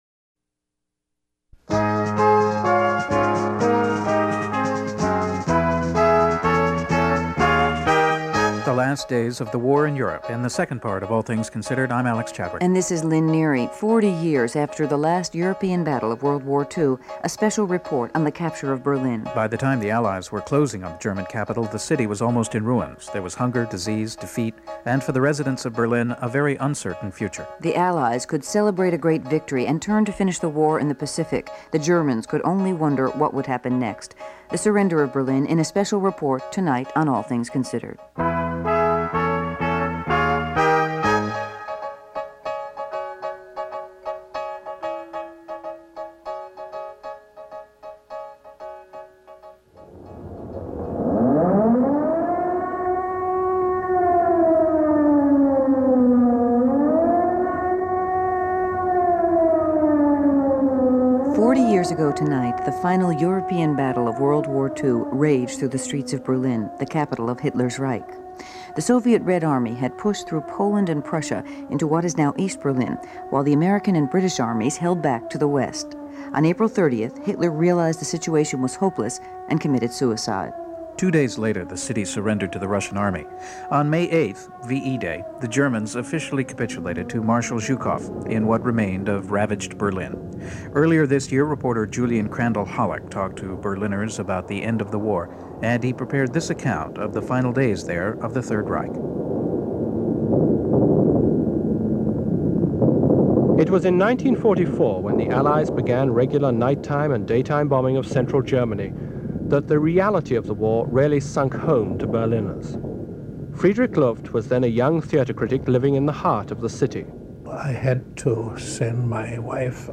Berlin Quintet contains five pieces about Berlin before the fall of the Wall in 1989. They were all recorded in late 1984 and early 1985 and broadcast in 1985 on NPR's All Things Considered and Morning Edition, and by WBUR-FM, Boston. They are all in Mono.